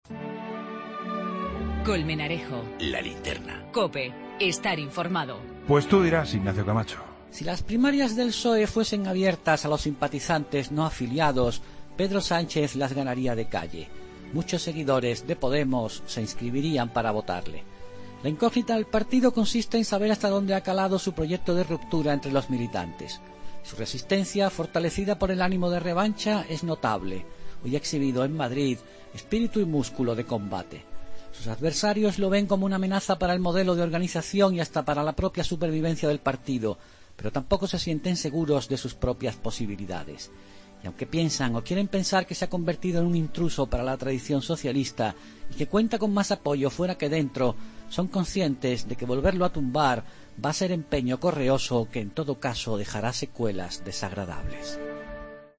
AUDIO: El comentario de Ignacio Camacho en 'La Linterna' sobre las primarias del PSOE y la posición de Pedro Sánchez